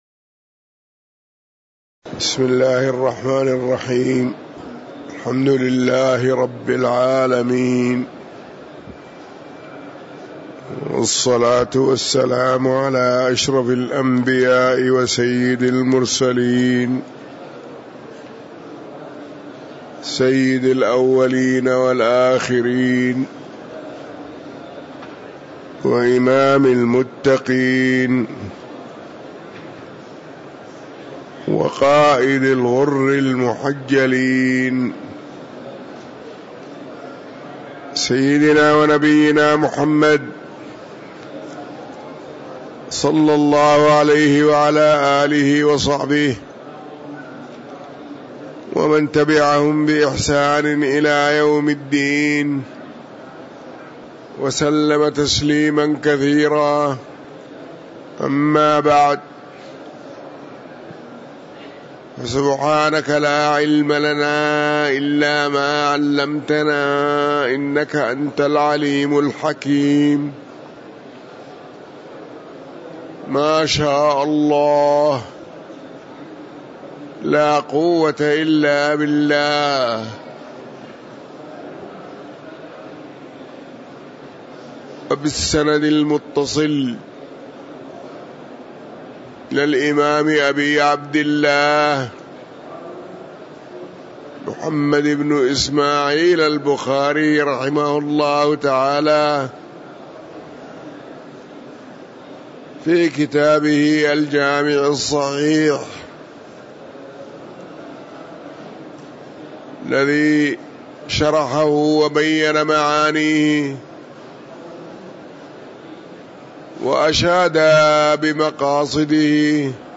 تاريخ النشر ١٩ ربيع الأول ١٤٤٥ هـ المكان: المسجد النبوي الشيخ